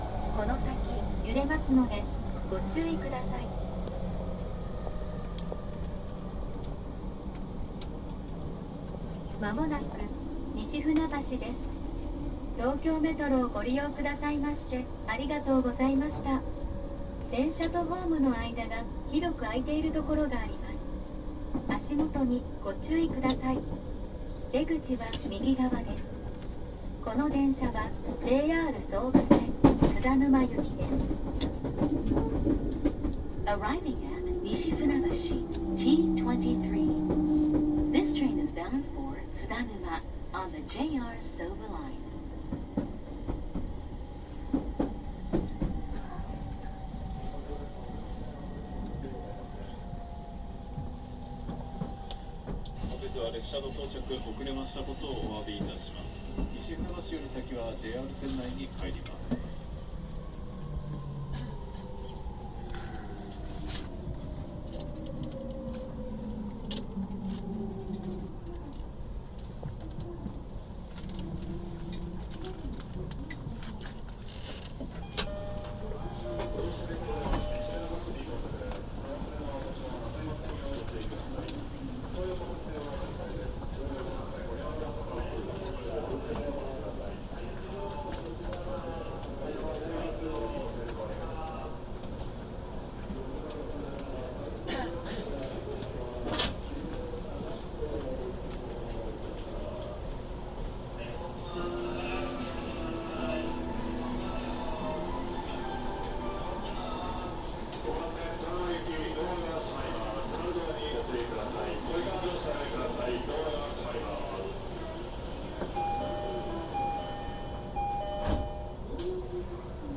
東京メトロ東西線はJR総武線に直通する時の報告（混んでいたから、音のみ） 浦安駅→西船橋駅：東京メトロのアナウンサー 西船橋駅：東京メトロの出発メロディ→JRのドア閉めの音 西船橋駅→船橋駅：JRのアナウンサー